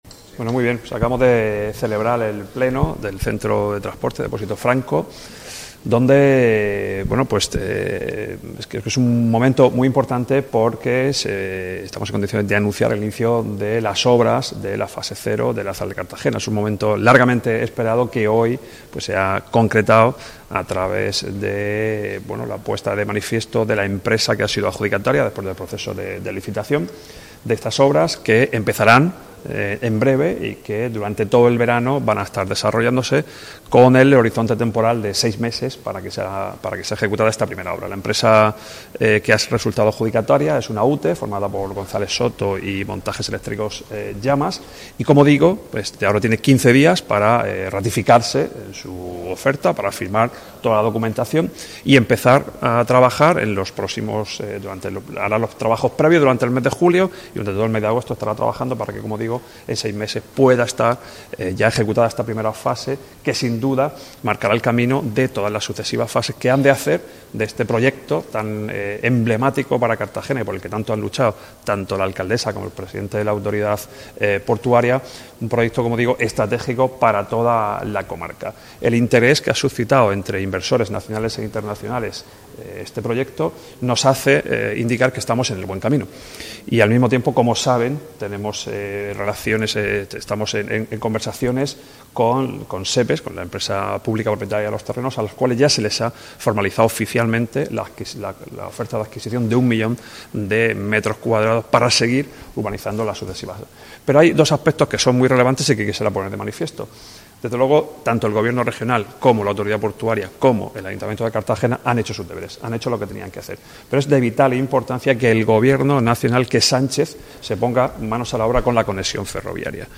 Enlace a Declaraciones Pleno Depósito Franco.